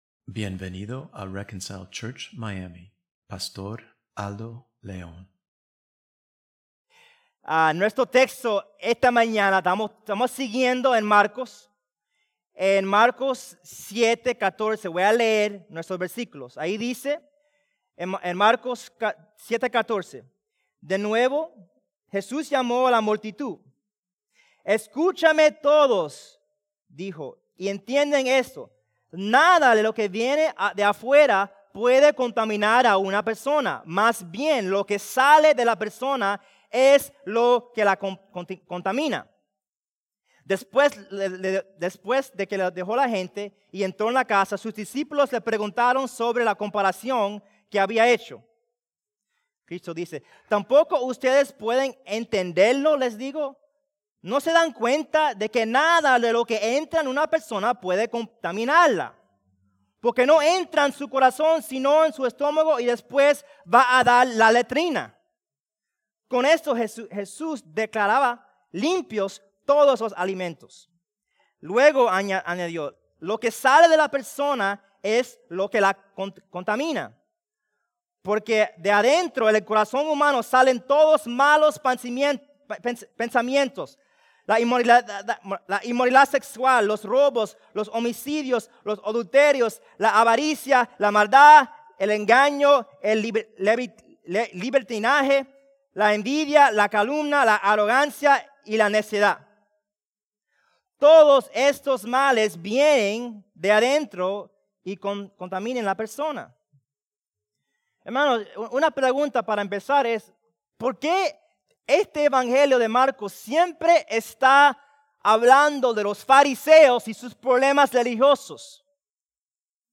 (Mk 7:14-23) Recording of Service in English